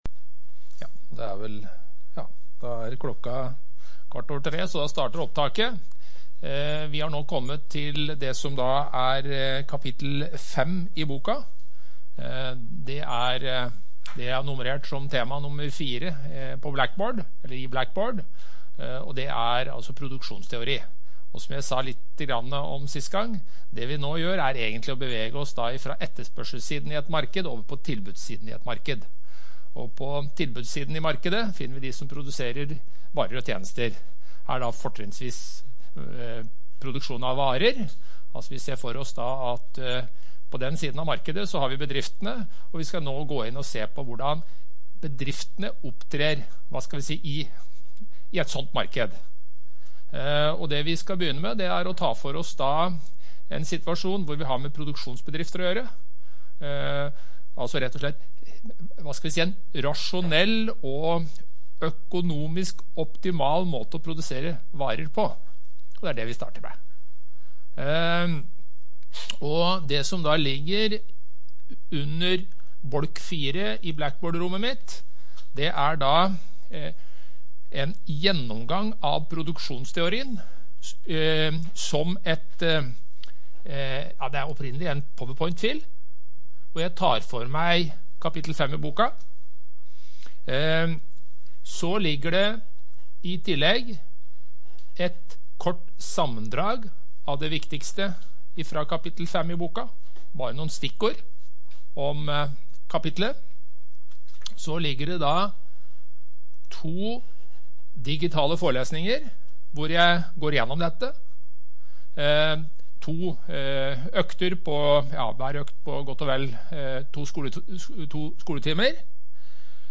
Rom: C007